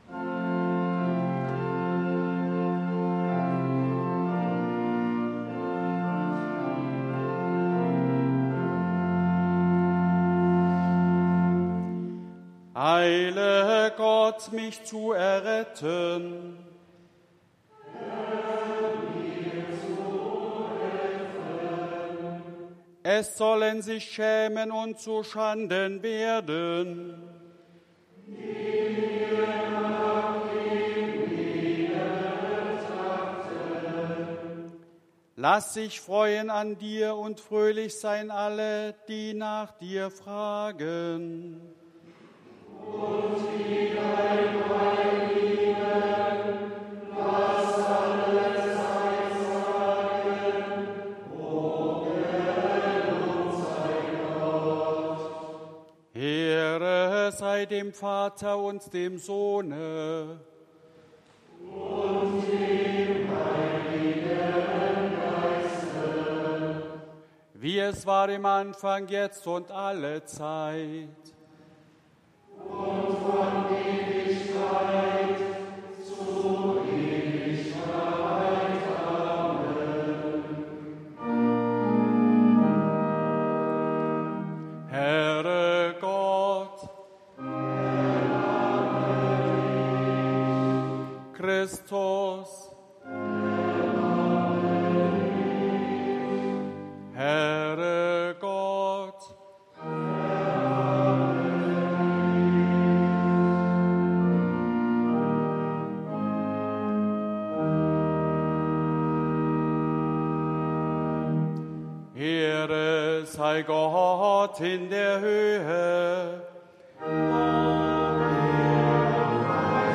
Eingangsliturgie Ev.-Luth.
Audiomitschnitt unseres Gottesdienstes am 11.Sonntag nach Trinitatis 2024